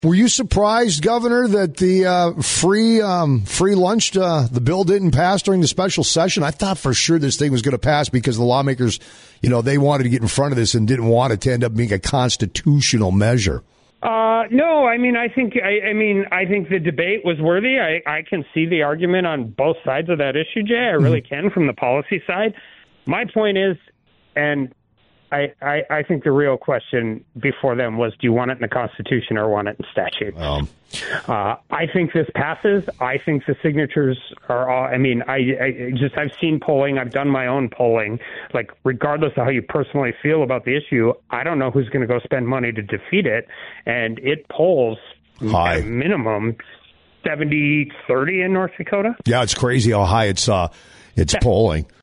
Governor Kelly Armstrong’s conversation